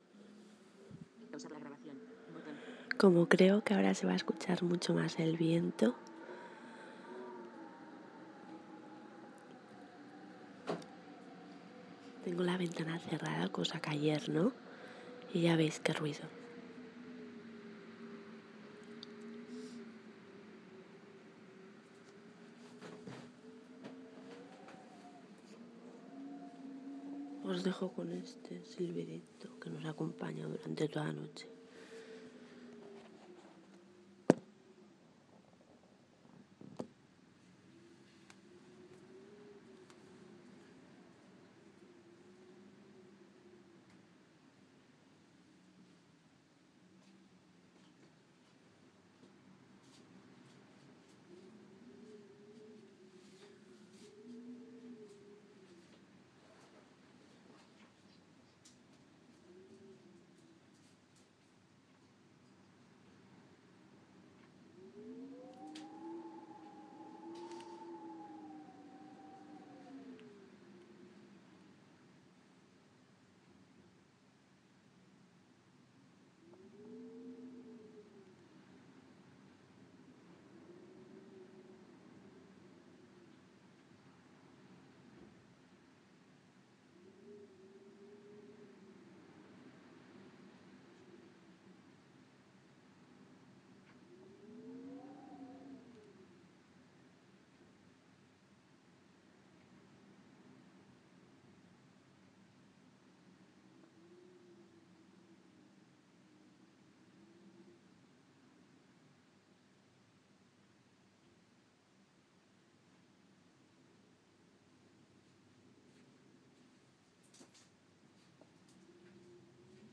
viento en la Gomera 2